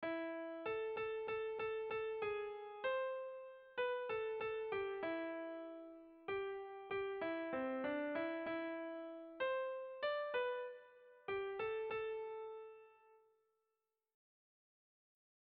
Erromantzea
AB